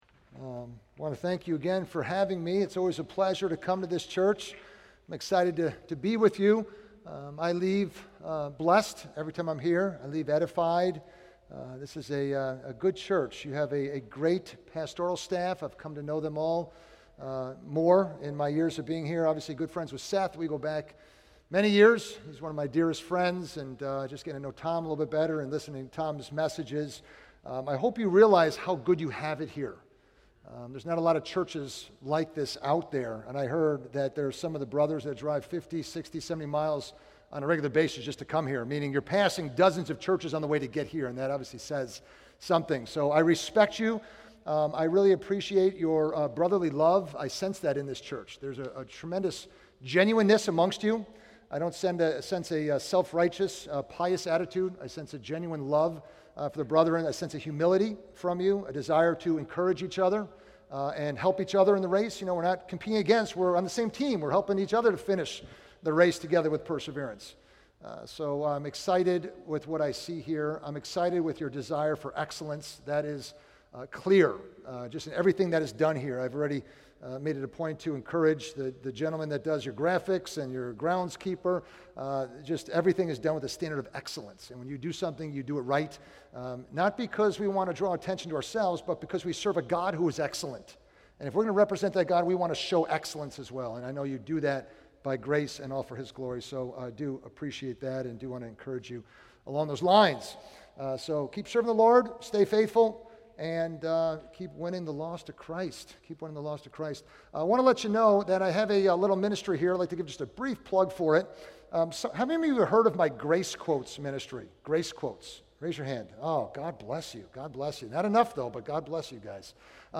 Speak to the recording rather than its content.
Men Men's Retreat - 2016 Audio ◀ Prev Series List Next ▶ Previous 1.